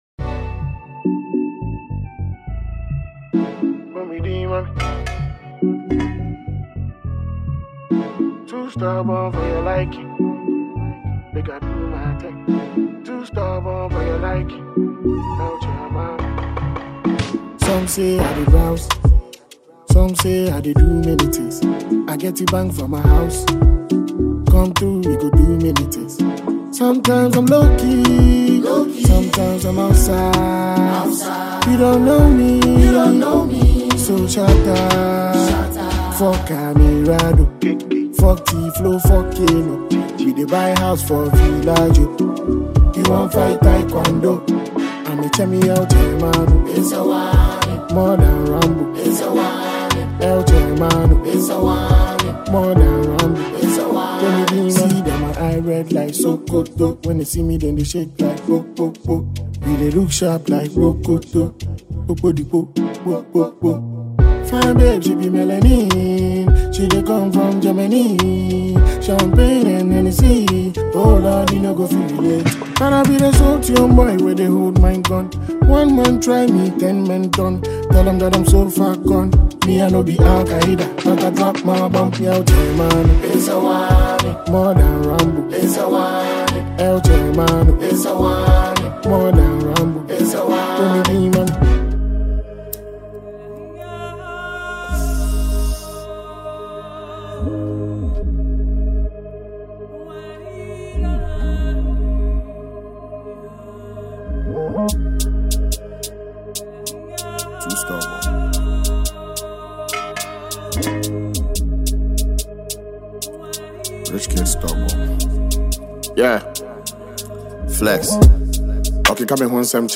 Ghana Music
Award-winning Ghanaian rapper